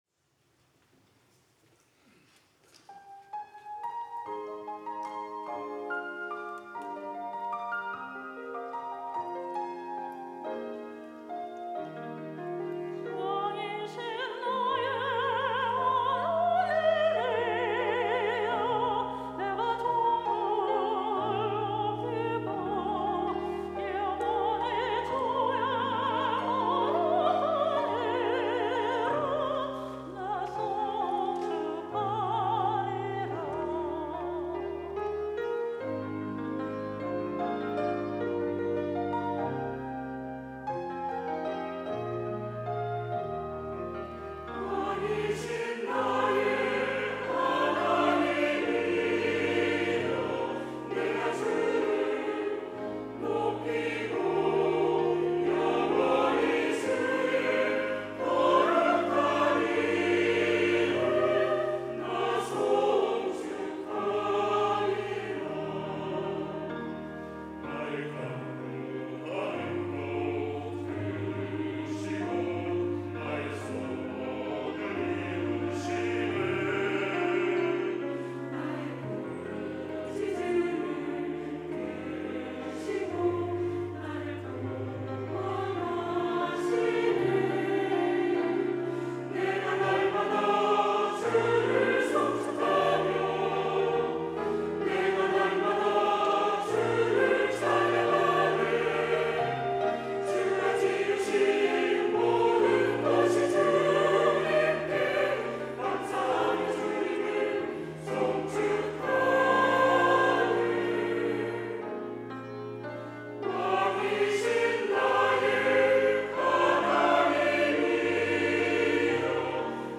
호산나(주일3부) - 왕이신 나의 하나님
찬양대